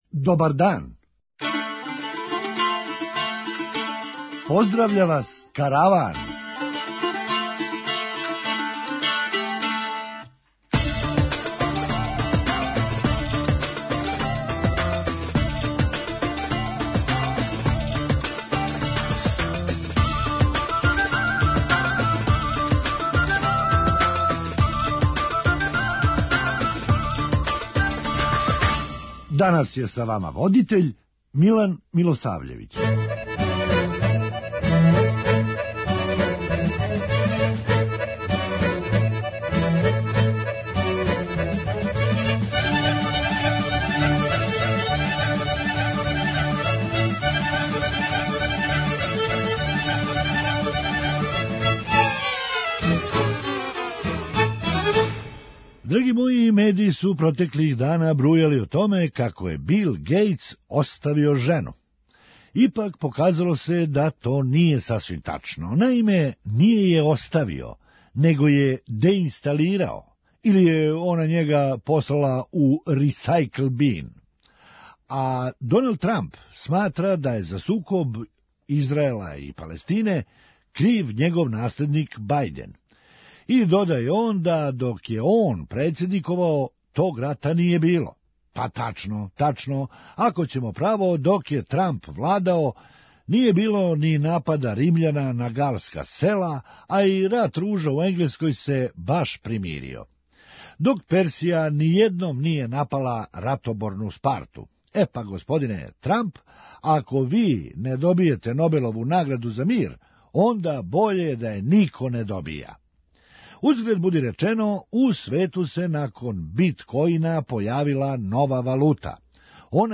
Хумористичка емисија
Јер, некада су црногорски бруцоши имали само тридесет година а данас већ имају по четрдесет! преузми : 9.13 MB Караван Autor: Забавна редакција Радио Бeограда 1 Караван се креће ка својој дестинацији већ више од 50 година, увек добро натоварен актуелним хумором и изворним народним песмама.